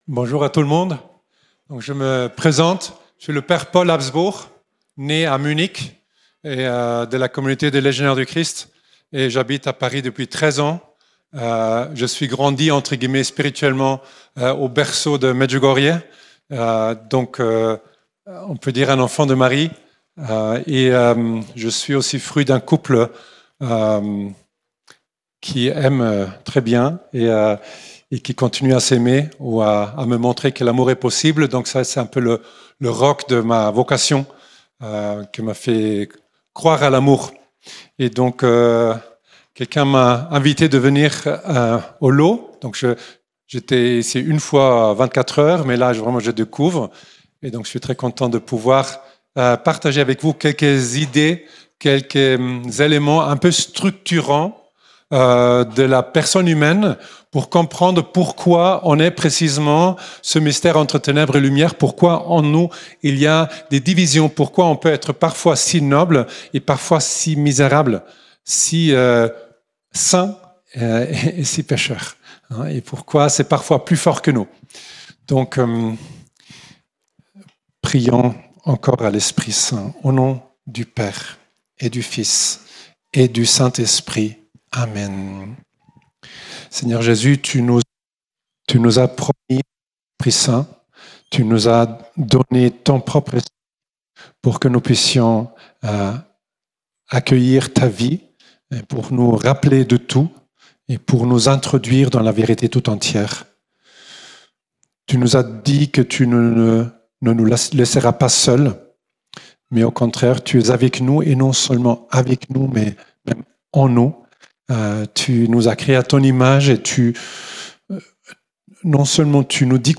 Notre Dame du Laus - Festival Marial